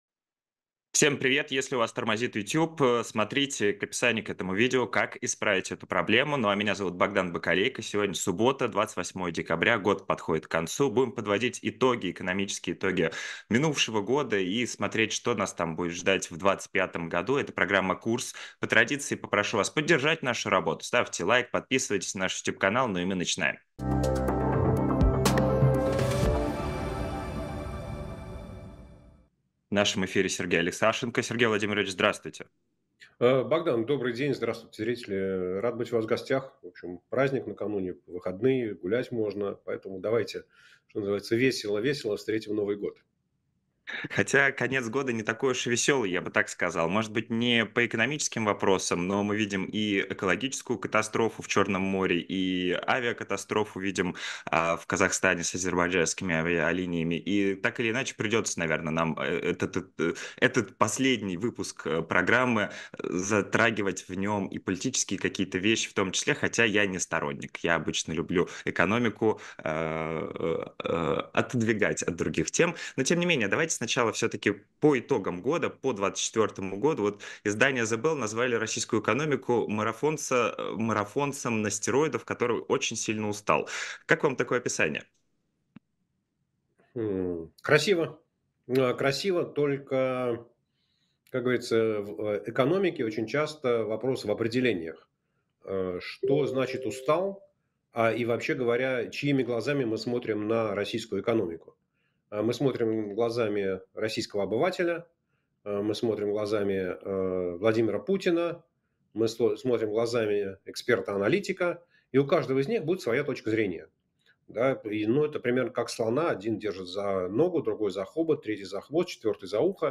Сергей Алексашенко экономист